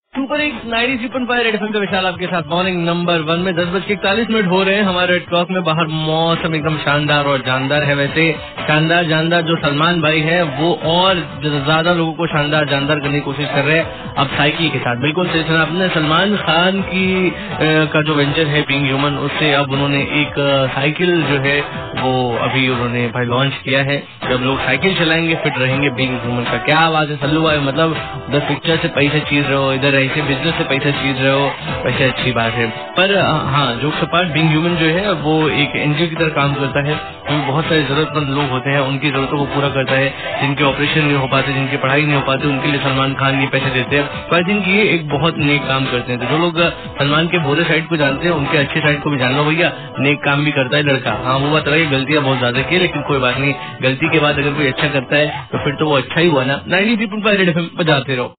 RJ TALKING ABOUT SALMAN KHAN(BEING HUMAN)